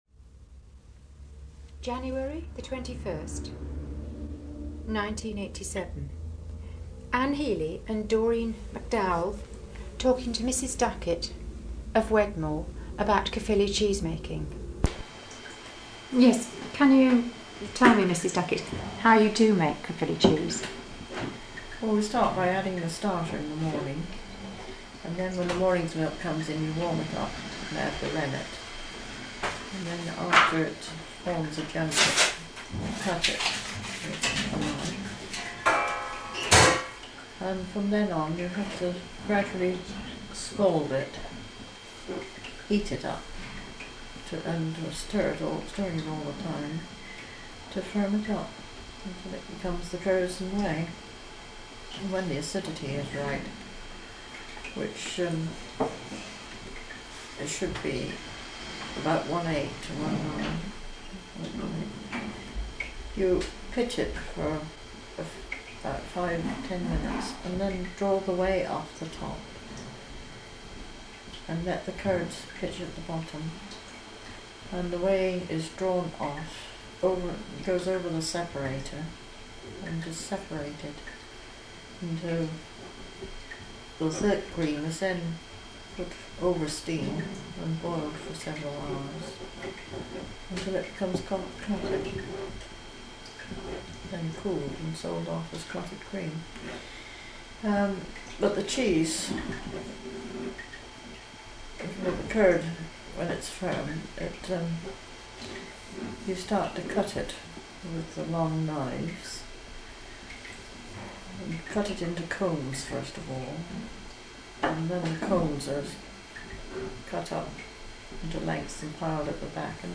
For access to full interview please contact the Somerset Heritage Centre.